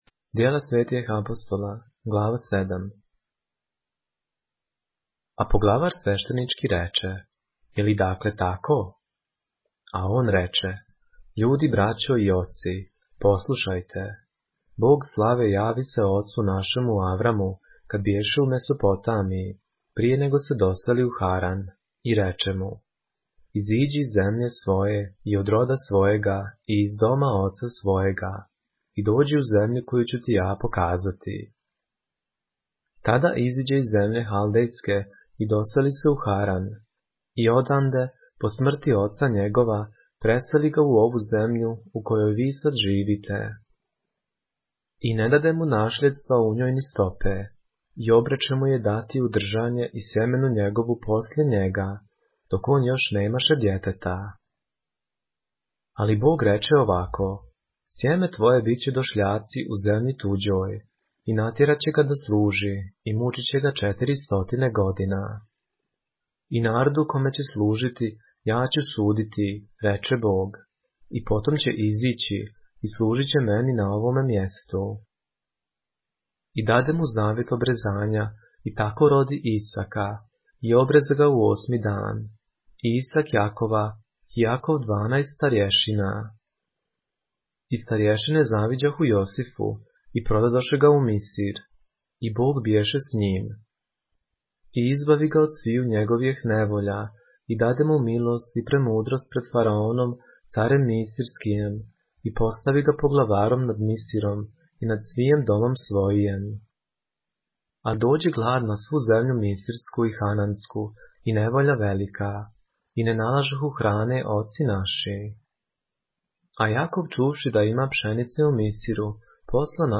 поглавље српске Библије - са аудио нарације - Acts, chapter 7 of the Holy Bible in the Serbian language